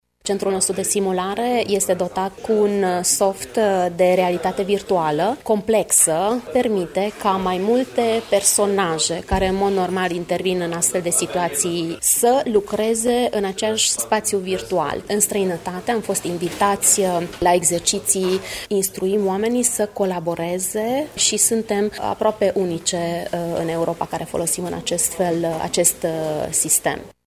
La acest stagiu se folosesc tehnologii de ultimă generație precum programul de realitate virtuală, pentru care țara noastră este apreciată în Europa. Medicul de urgență